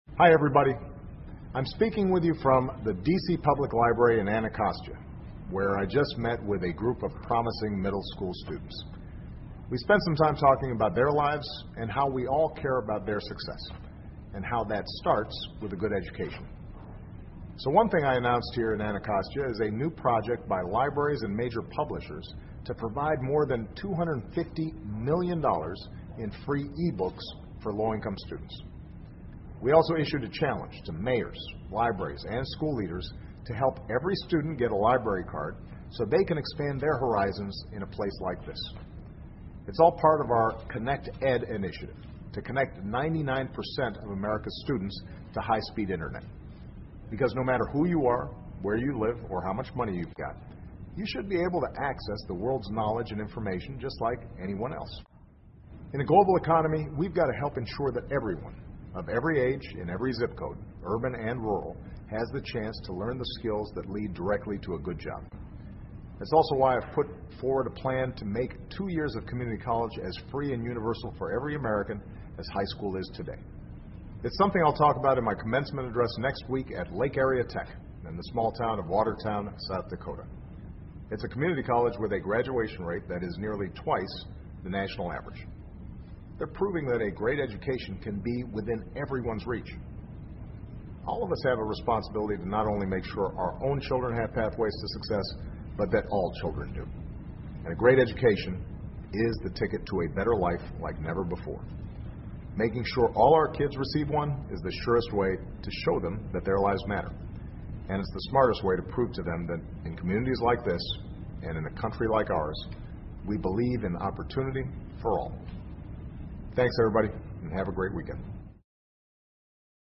奥巴马每周电视讲话：总统呼吁每个孩子都受到良好教育 听力文件下载—在线英语听力室